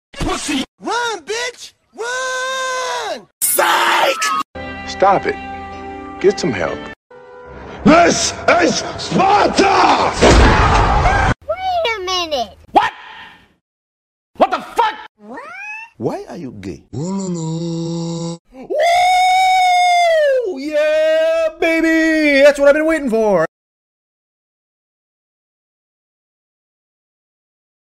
10+ FREE Sound Effects for sound effects free download